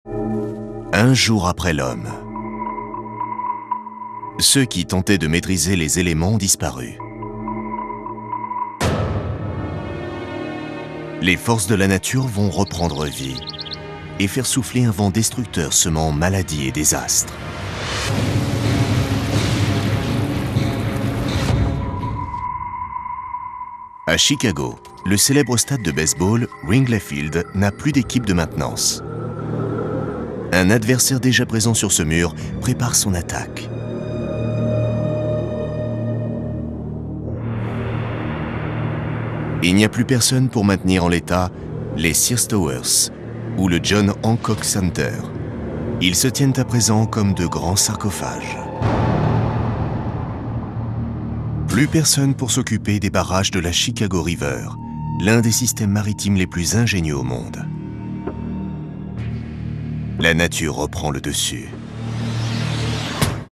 Prestation voix-off pour "La Terre après les hommes" : ton viril, factuel et posé
Inquiétant, factuel, droit.
Extrait de la série de documentaire « La Terre après les hommes » diffusé sur la chaine Syfy et « Le magazine de la fin du monde ».
Pour ce faire, j’ai utilisé une tonalité de voix grave. Mon ton était droit, factuel, informatif, viril et posé, en accord avec le thème de l’anticipation.